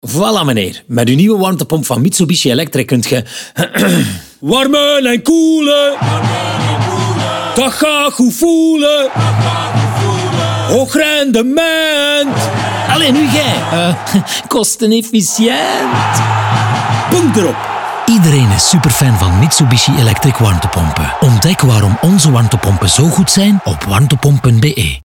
Accessible, Friendly, Soft, Young, Versatile